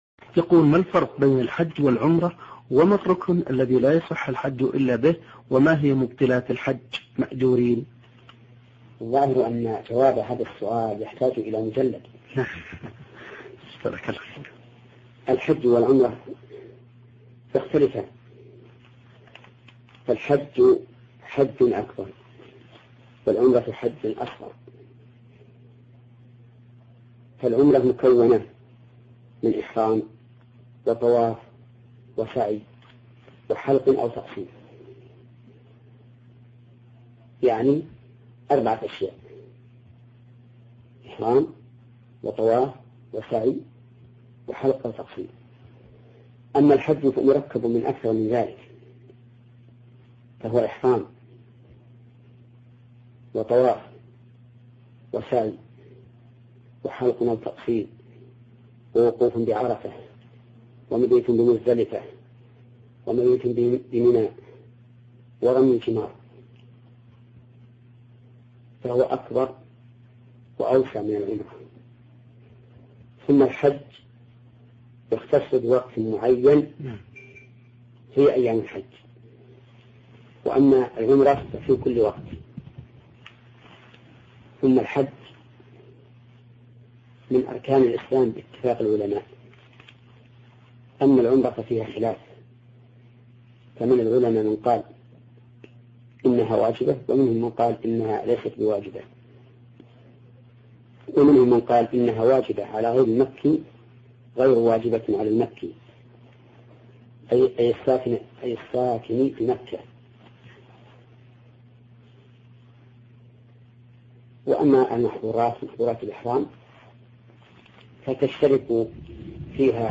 الفتاوى  |  ما الفرق بين الحج والعمرة؟